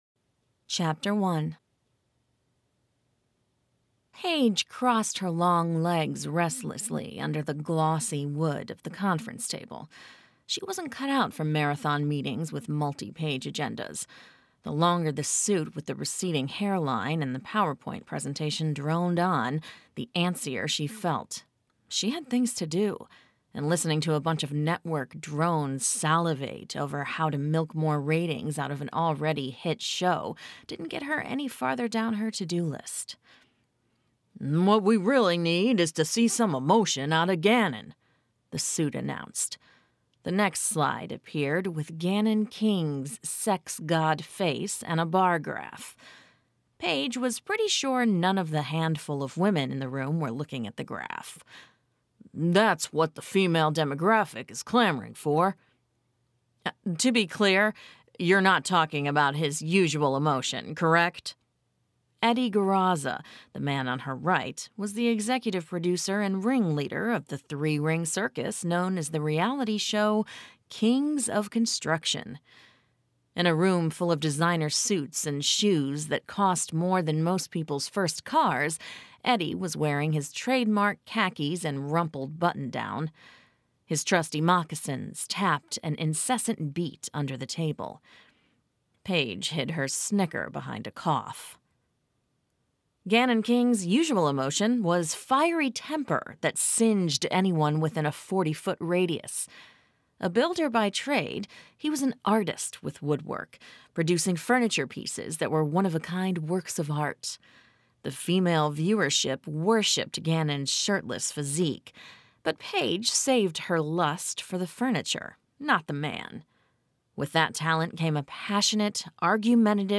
• Audiobook